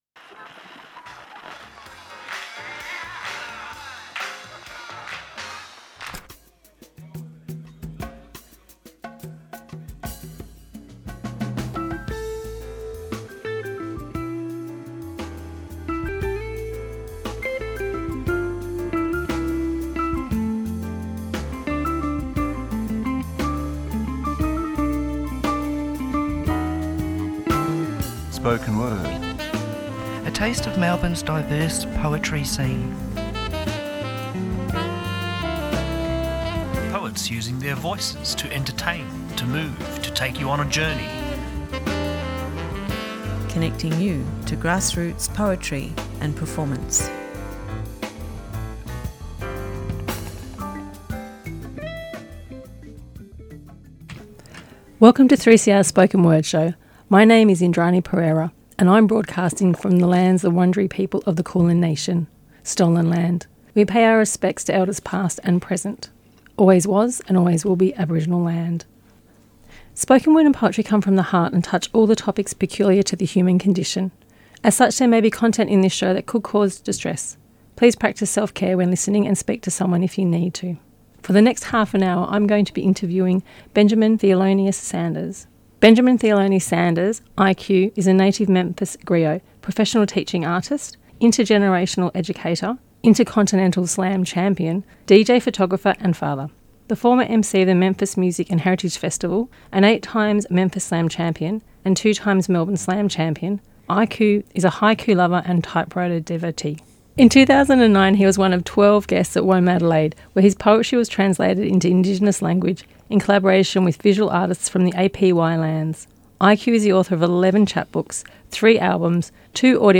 Each guest shares a favourite poem (or poems) written by a poet who has influenced them over the years.
Tweet Spoken Word Thursday 9:00am to 9:30am A program dedicated to the eclectic world of poetry and performance. Guests are contemporary poets who read and discuss their works.